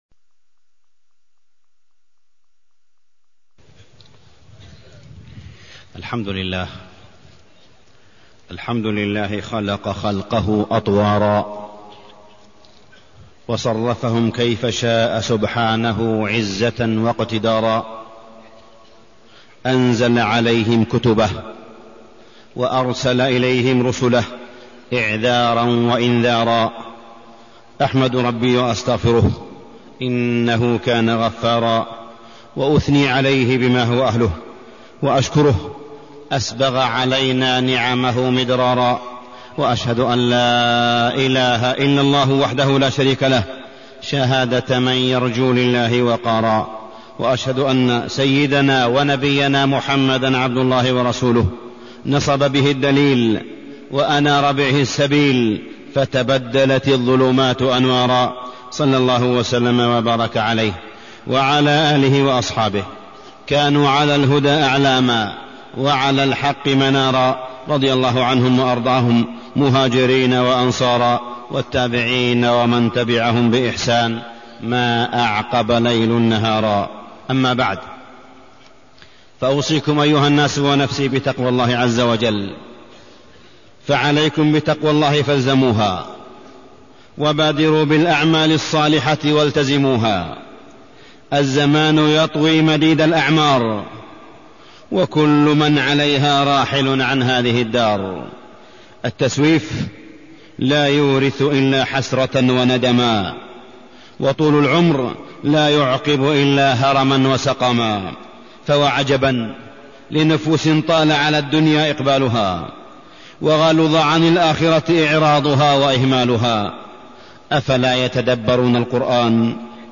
تاريخ النشر ٢١ ذو الحجة ١٤٢١ هـ المكان: المسجد الحرام الشيخ: معالي الشيخ أ.د. صالح بن عبدالله بن حميد معالي الشيخ أ.د. صالح بن عبدالله بن حميد ارتباط المسلم بالكعبة